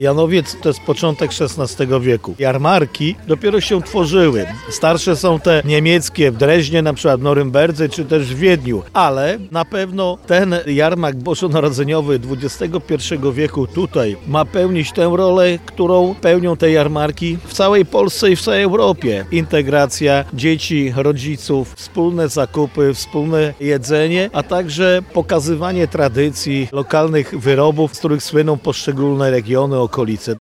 W Janowcu dobiega końca II Jarmark Bożonarodzeniowy. W scenerii ruin zamkowych na stoiskach prezentują swoje produkty lokalni rękodzielnicy i koła gospodyń wiejskich.